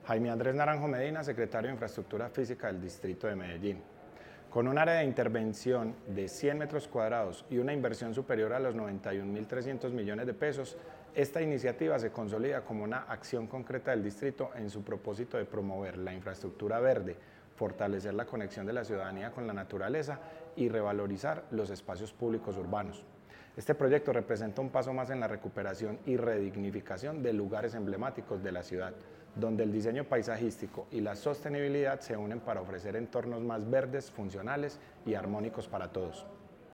Declaraciones-secretario-de-Infraestructura-Fisica-Jaime-Andres-Naranjo-Medina.mp3